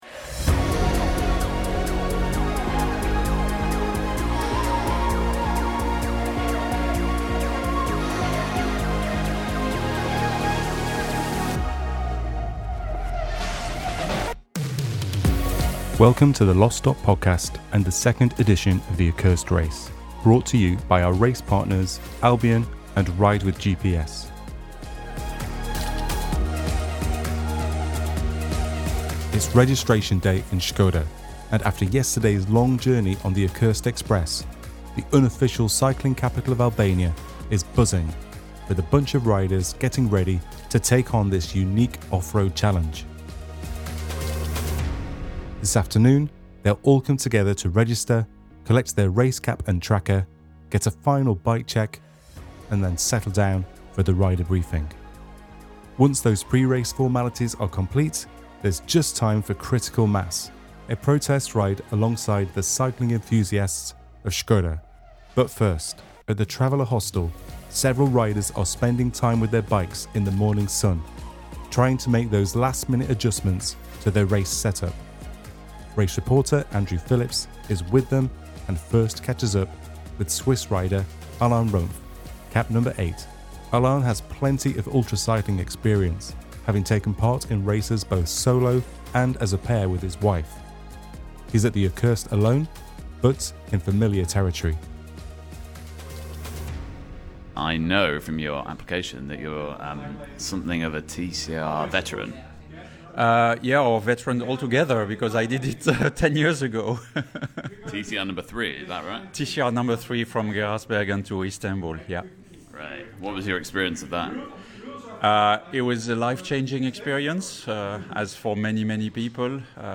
Race reporters capture the ambience at registration day in this podcast episode, while riders talk through their bike setups and what they are anticipating to come from the race ahead.